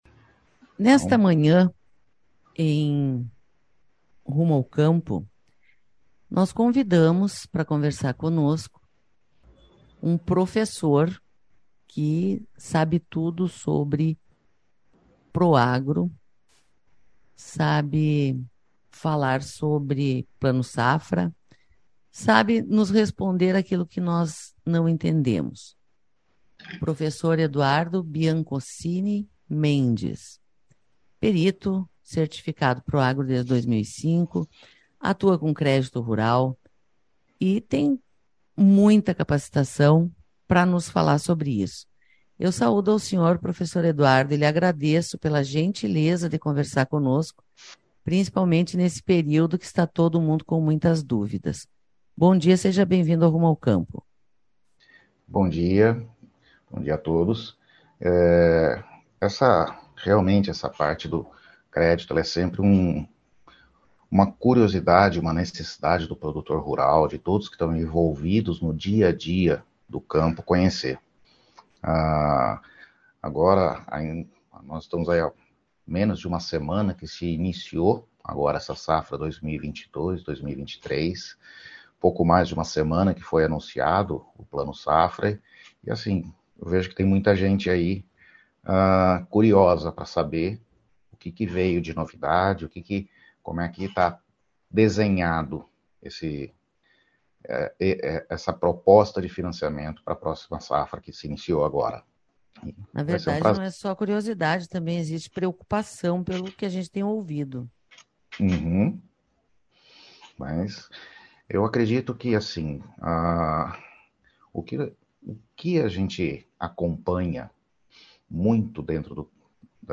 Entrevista: Plano Safra para ser entendido e compreendido